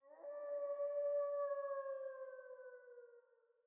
Babushka / audio / sfx / Animals / SFX_Wolf_Howl_02.wav
SFX_Wolf_Howl_02.wav